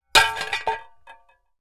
Metal_67.wav